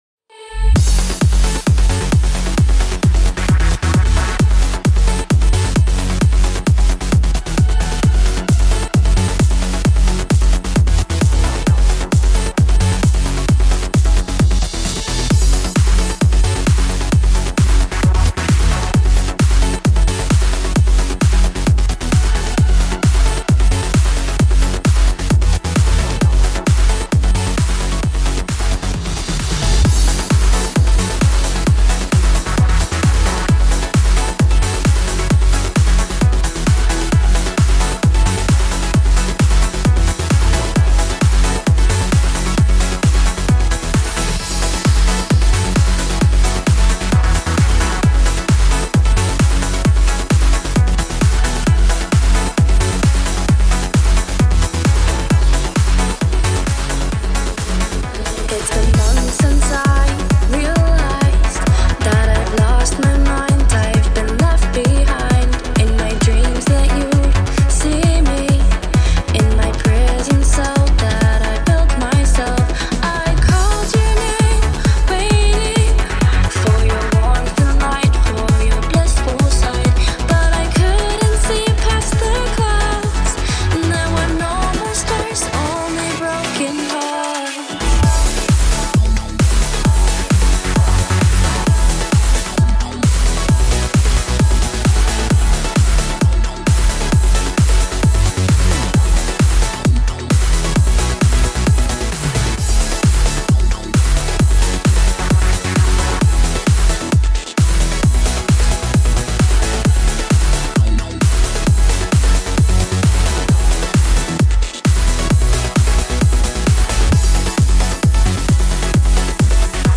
DOWNLOAD (fixed mastering with a bit less bass):